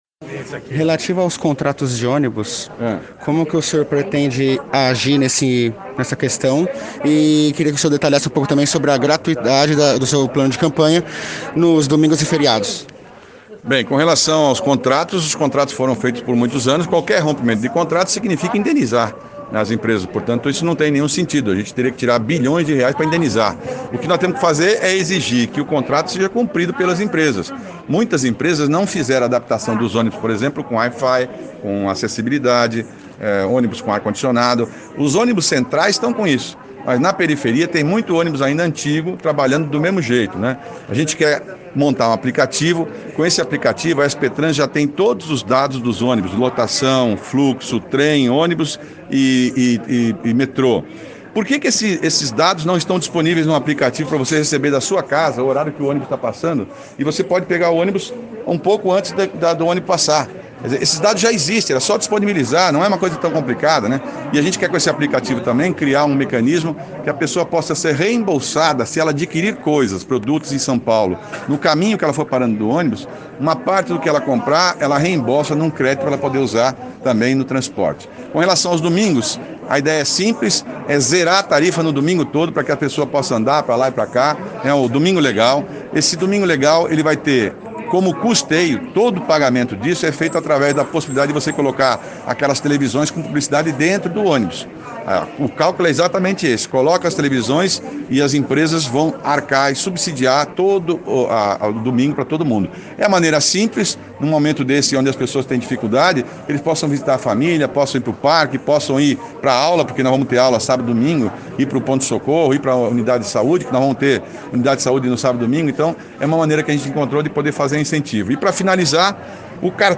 O candidato à Prefeitura de São Paulo, Márcio França (PSB), em entrevista para o Diário do Transporte nesta terça-feira, 06 de outubro de 2020, falou sobre plano de governo para a área de mobilidade, caso seja eleito.